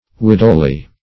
Widowly \Wid"ow*ly\, a. Becoming or like a widow.